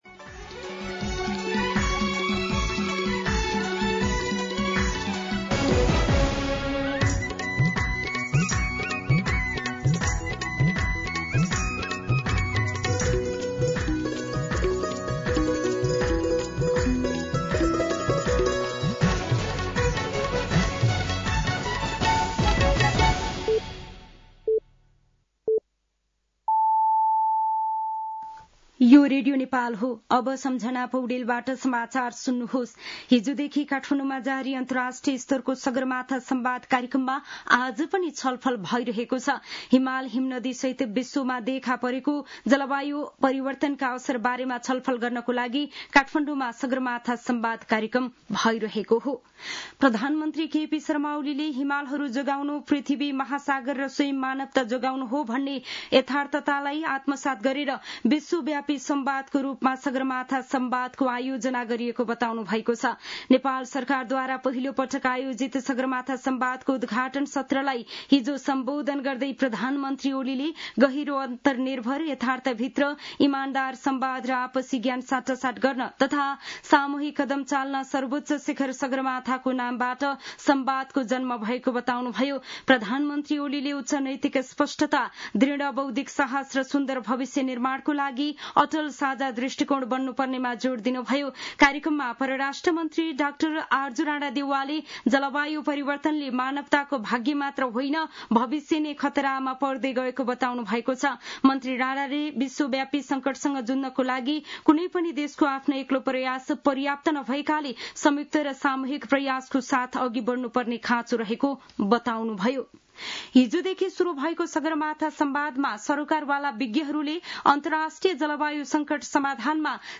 मध्यान्ह १२ बजेको नेपाली समाचार : ३ जेठ , २०८२
12-pm-Nepali-News-4.mp3